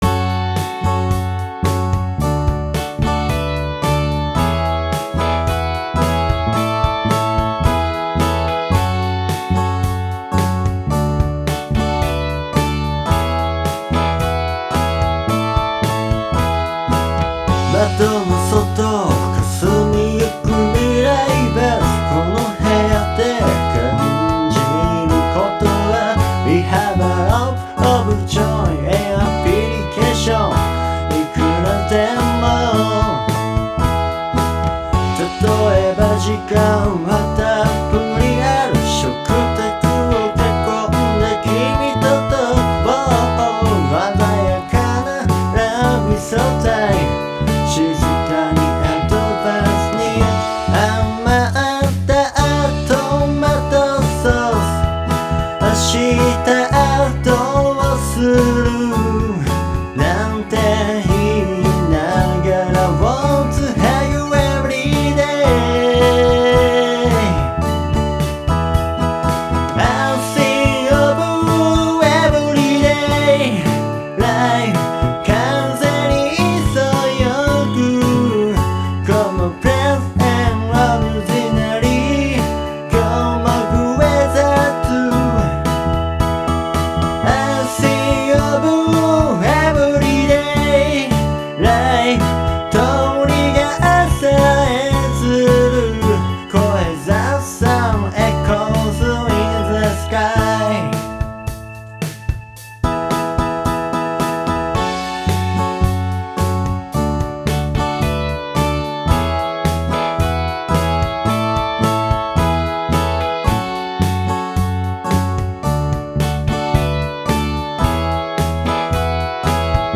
LIFE-仮歌.m4a